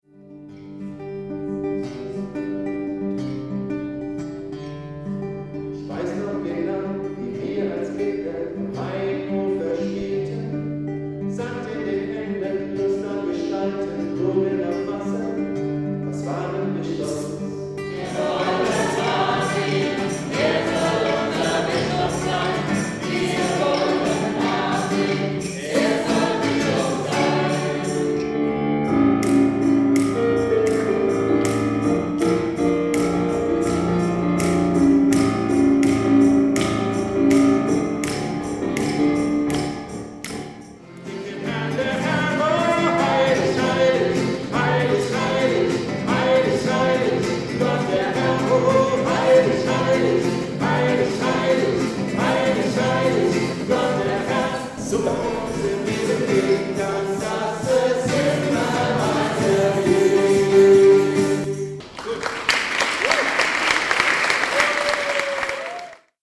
Musikalische Lesung
Sonntag, 28.10.2012, 18 Uhr in Mommenheim - kath. Kirche
Am Klavier